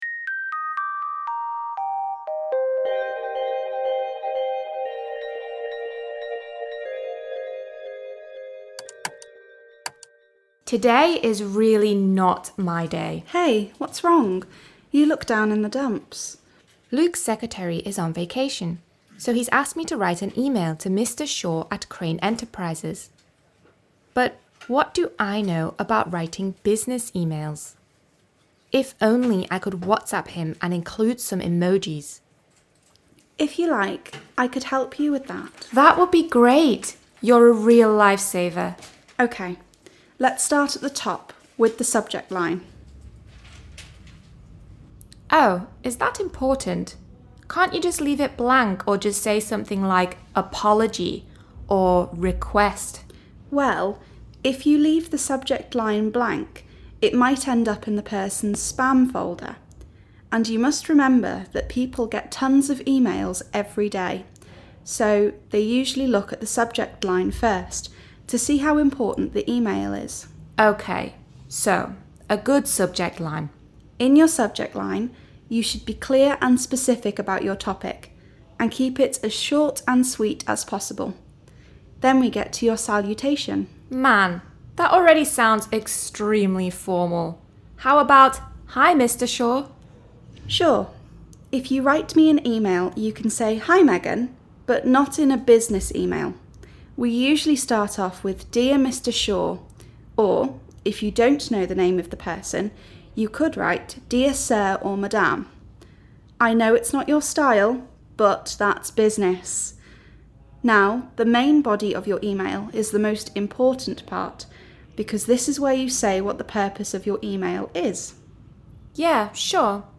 The complete Lesson 1